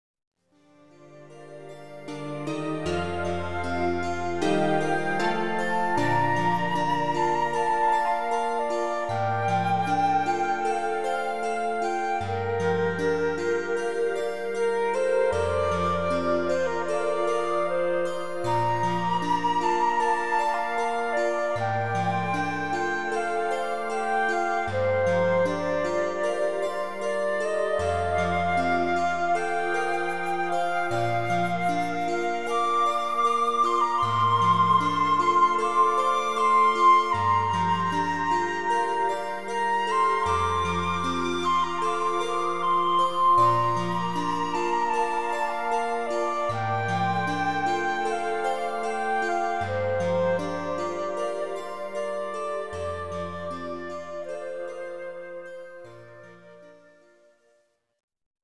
Relaxációs cd 50 perc zenével.